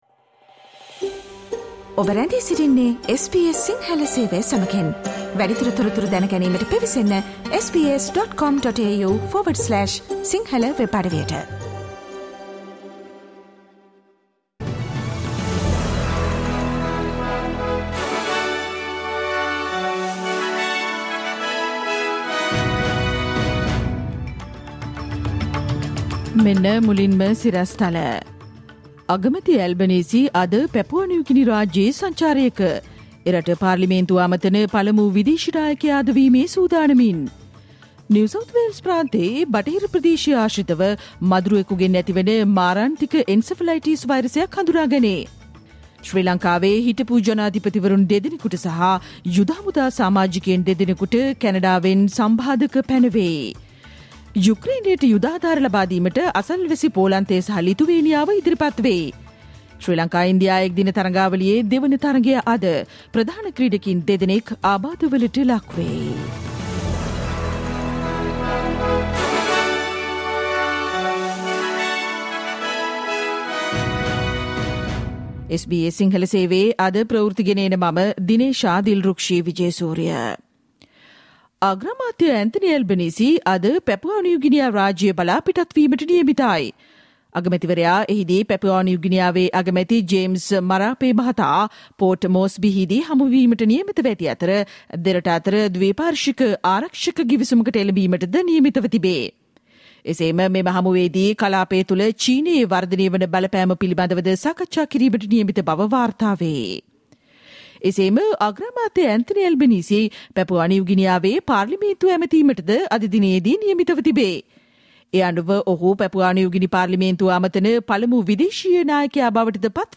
Listen to the SBS Sinhala Radio news bulletin on Thursday 12 January 2022